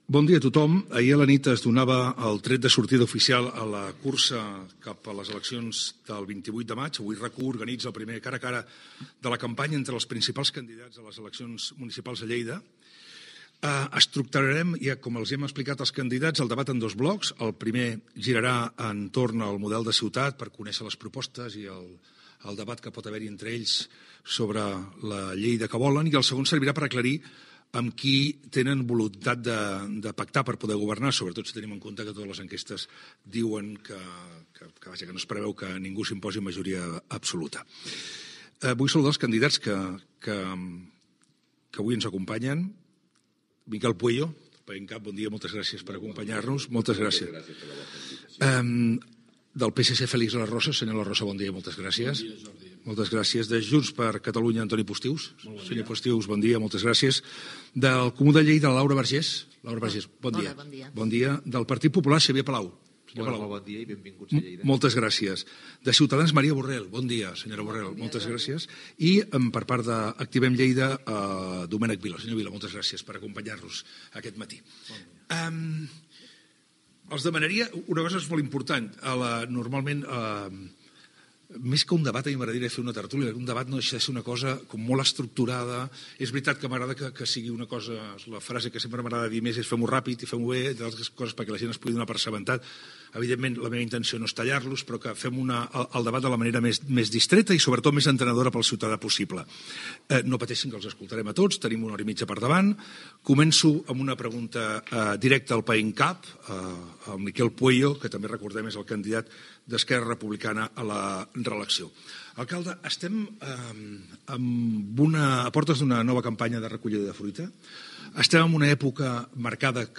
Presentació del debat a les eleccions municipals a l'Ajuntament de Lleida, intervenció inicial de Miquel Pueyo d'Esquerra Republicana.
Informatiu
FM